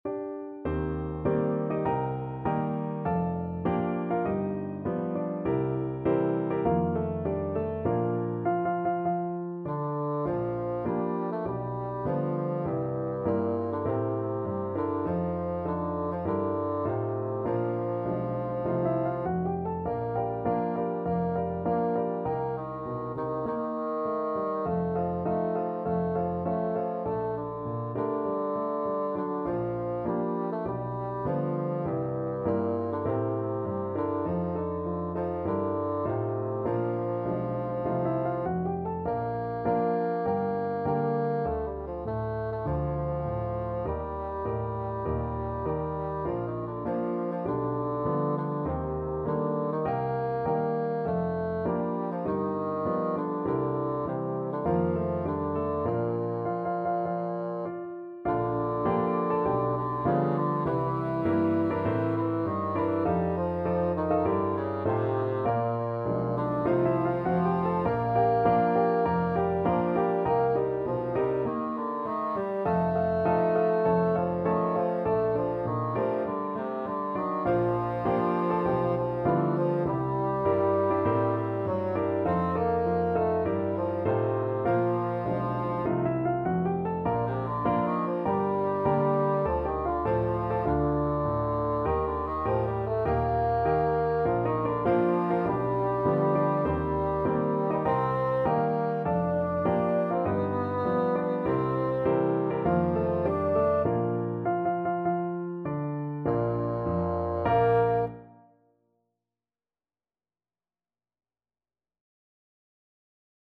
Bassoon
Bb major (Sounding Pitch) (View more Bb major Music for Bassoon )
4/4 (View more 4/4 Music)
Moderato = c. 100
G3-D5
Jazz (View more Jazz Bassoon Music)
Rock and pop (View more Rock and pop Bassoon Music)